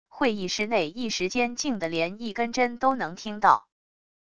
会议室内一时间静得连一根针都能听到wav音频生成系统WAV Audio Player